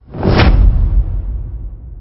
bgm-转场.mp3